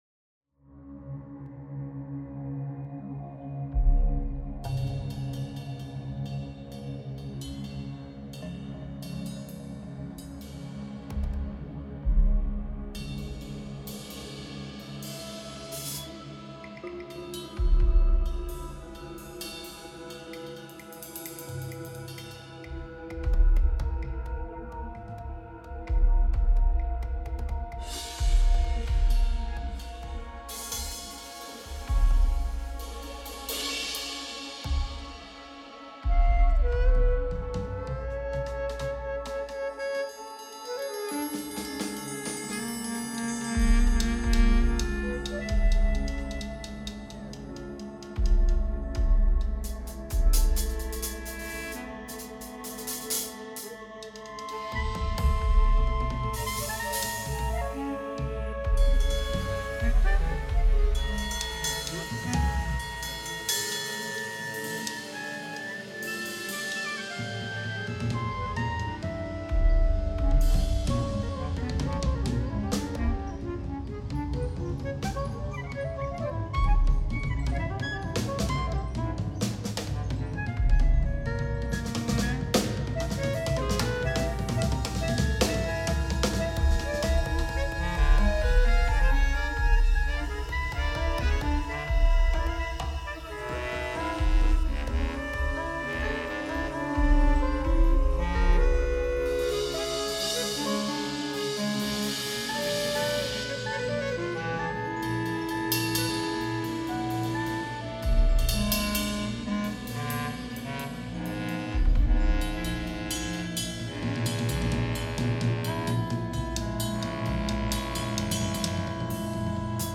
Sortilège pour : Clarinette basse, Clarinette, Saxophone soprano, Trombone, Séquences, Mini Moog, Contrebasse, Batterie, Drones...